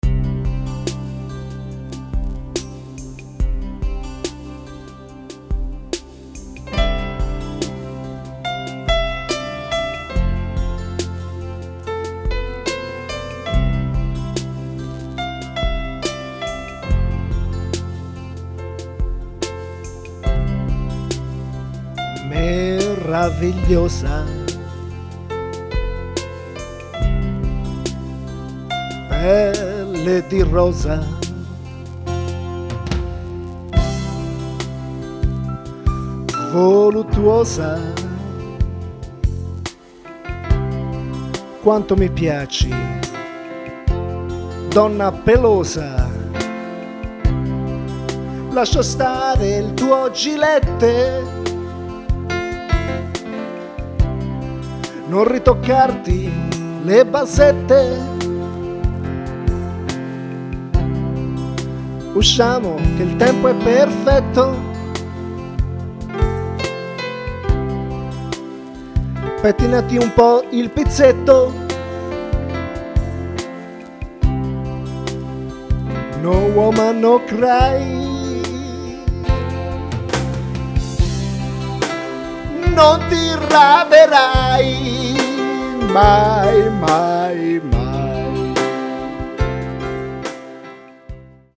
Un pezzettino improvvisato
tastiera